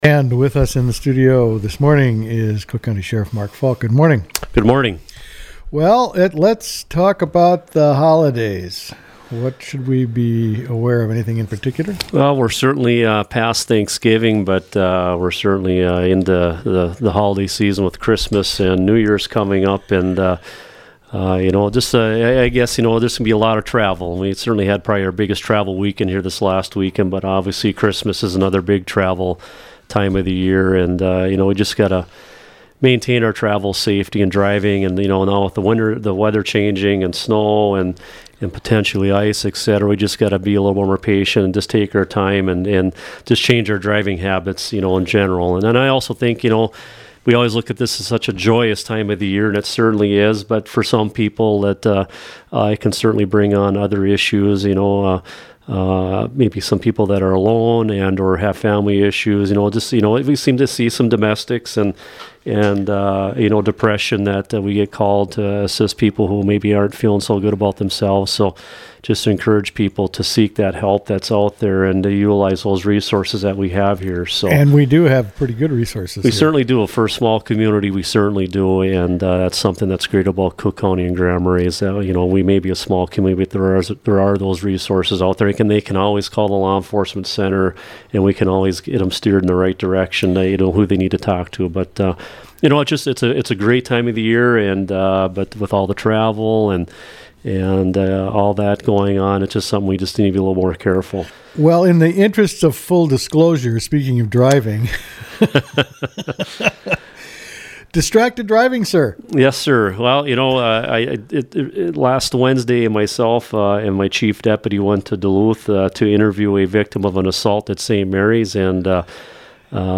Driving drives an interview with Sheriff Falk
FalkInterview.mp3